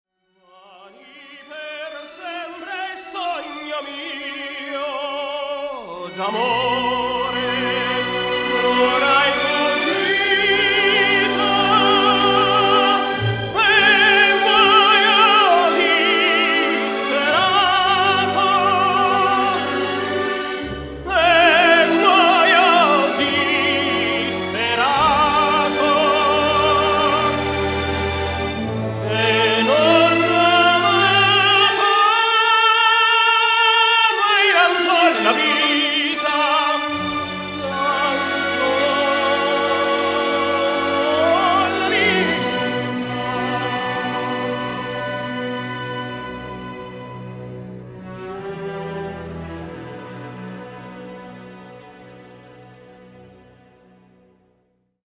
der klassische Tenor